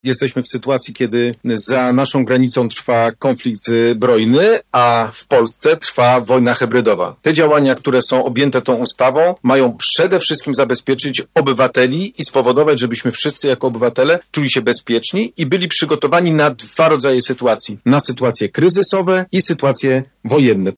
Adam Rudawski, wojewoda zachodniopomorski: